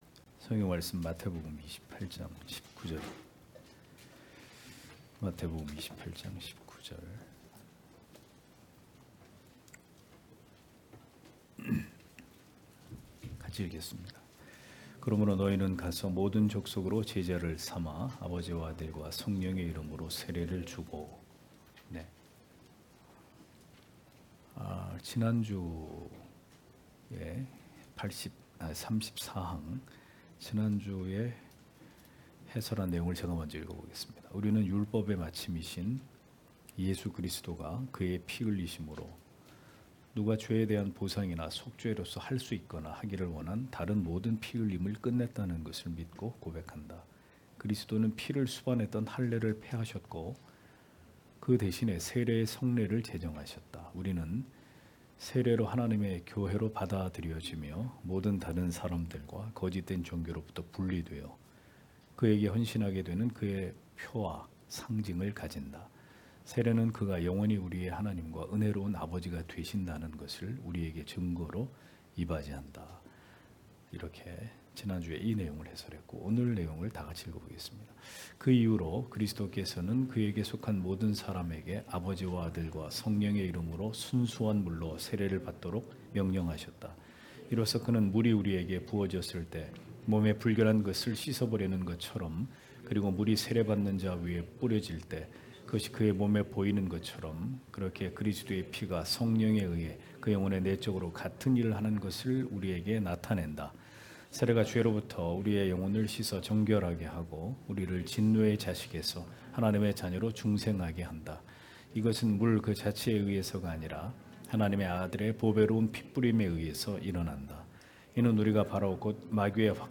주일오후예배 - [벨직 신앙고백서 해설 40] 제34항 세례의 성례(2) (마 28장 29절)